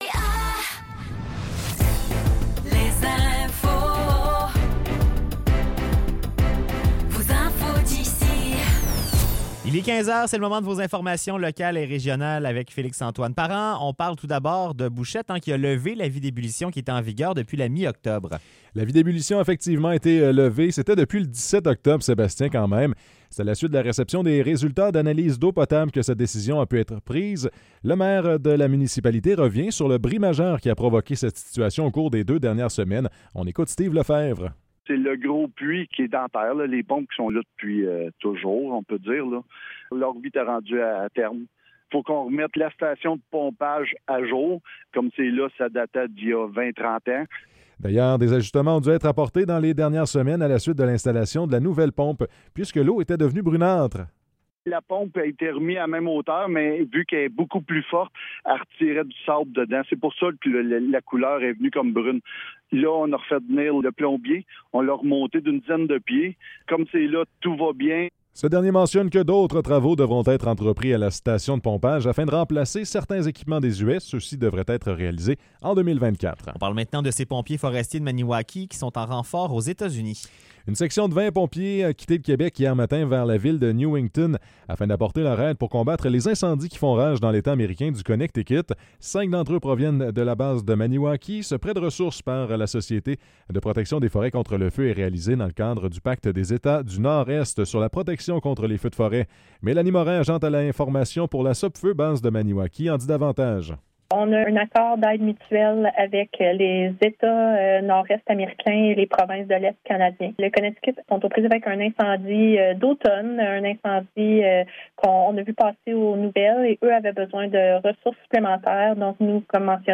Nouvelles locales - 1er novembre 2024 - 15 h